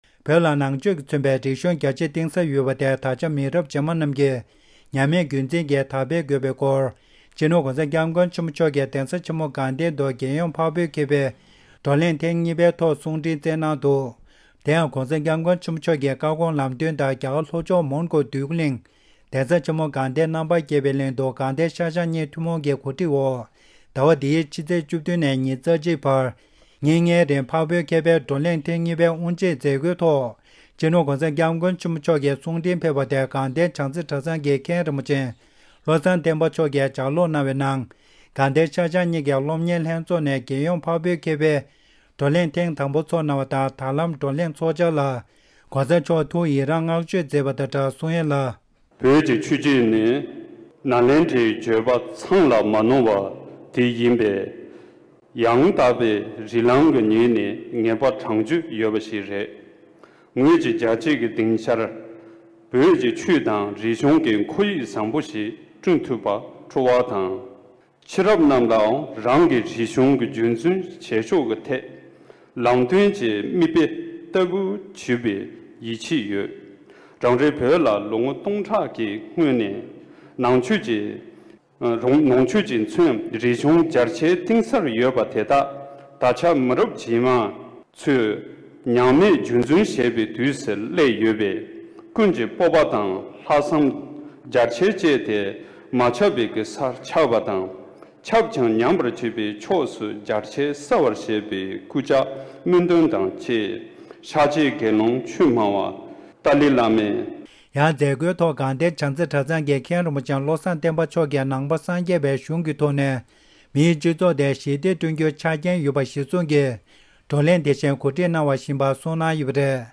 ས་གནས་ནས་བཏང་བའི་གནས་ཚུལ།
སྒྲ་ལྡན་གསར་འགྱུར། སྒྲ་ཕབ་ལེན།